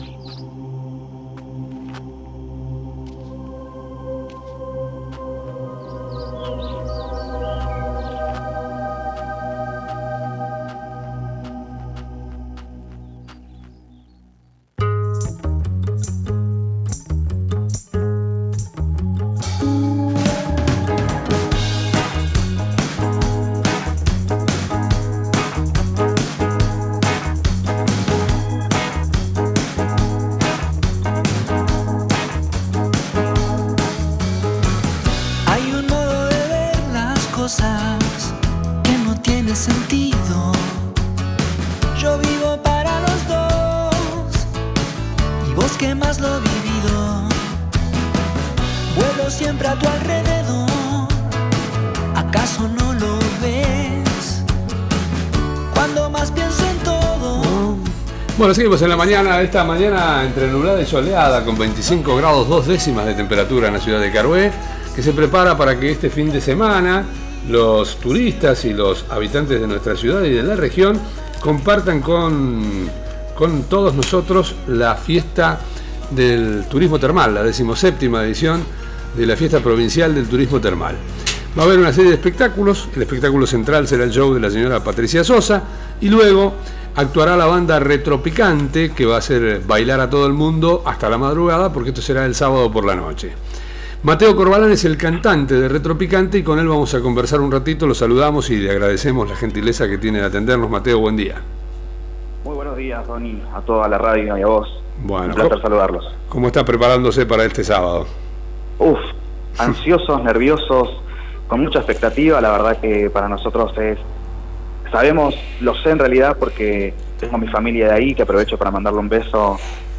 conversó con nosotros en la mañana de la radio. La banda se presentará el sábado en el marco de la 17° Fiesta del turismo termal.